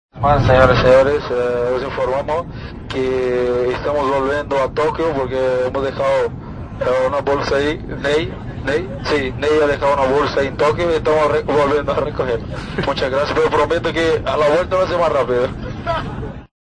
Dani Alves bromea en el avión de vuelta de Japón